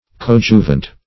Meaning of coadjuvant. coadjuvant synonyms, pronunciation, spelling and more from Free Dictionary.
Search Result for " coadjuvant" : The Collaborative International Dictionary of English v.0.48: Coadjuvant \Co*ad"ju*vant\, a. Cooperating.